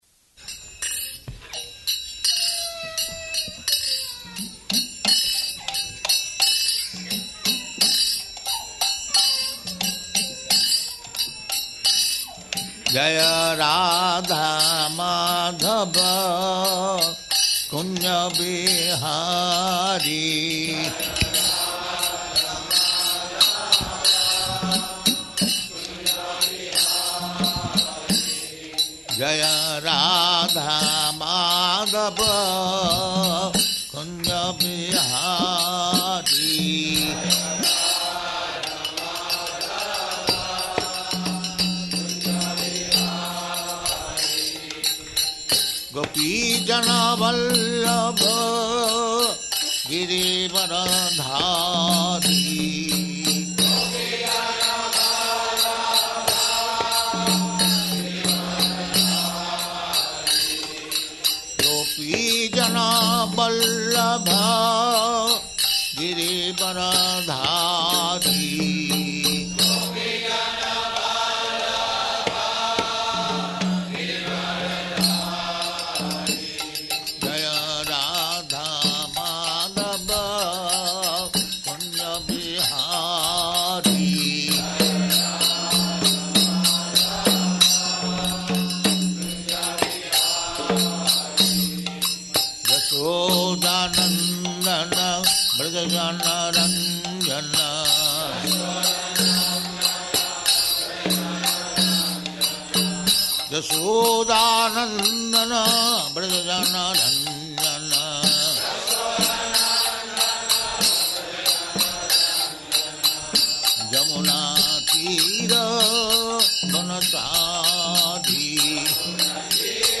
Location: Los Angeles
[Prabhupāda sings Jaya rādhā-mādhava, then chants prema-dhvani ]